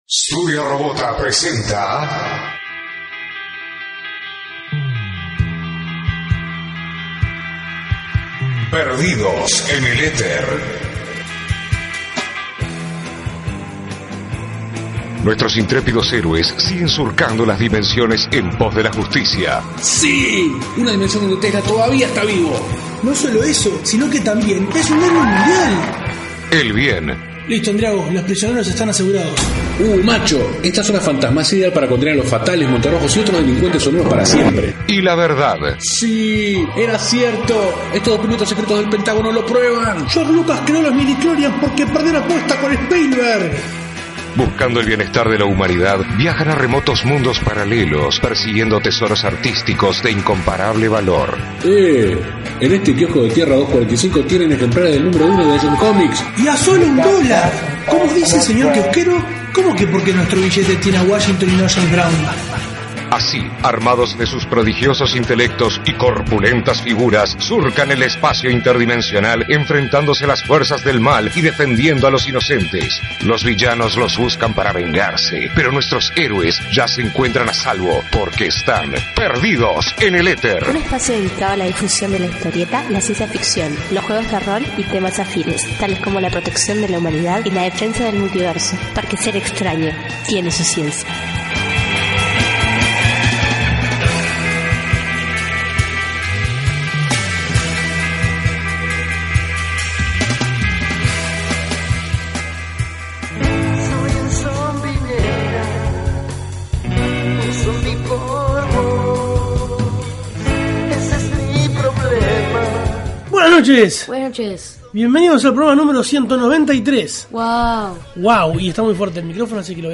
Perdidos En El Eter #193: Entrevista